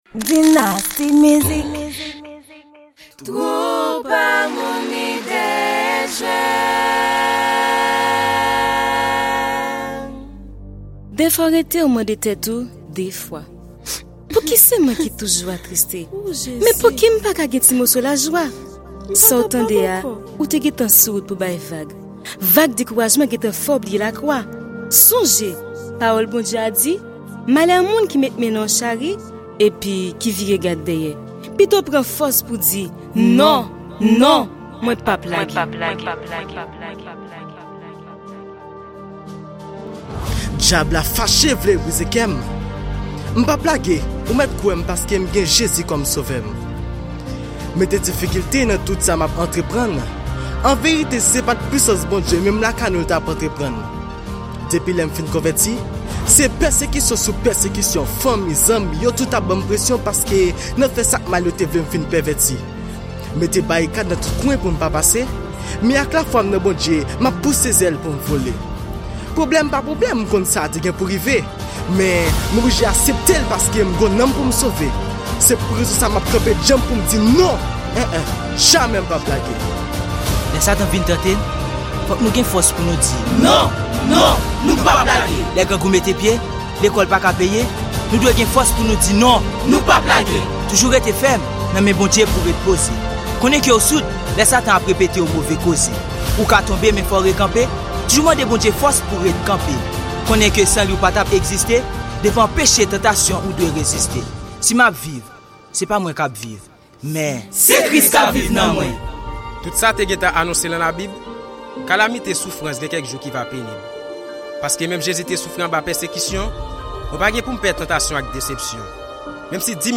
Genre: Religious.